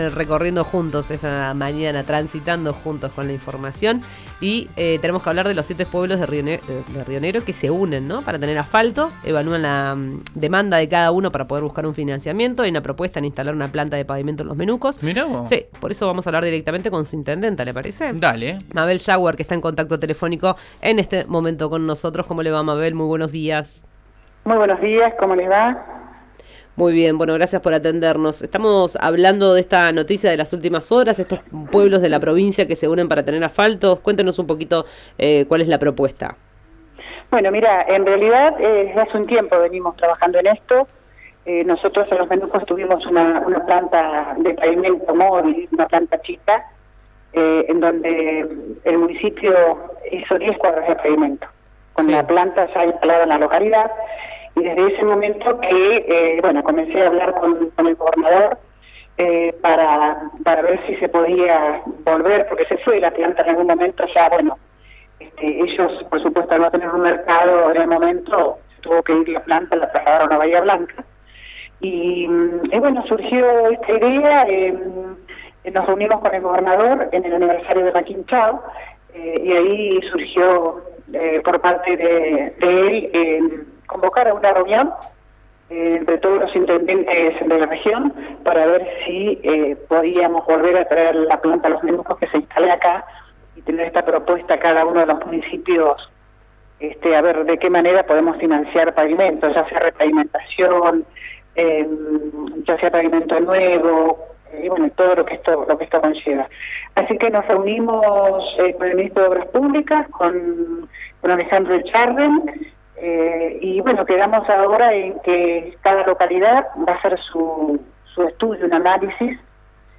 Escuchá a Mabel Yahuar, intendenta de Los Menucos, en RÍO NEGRO RADIO: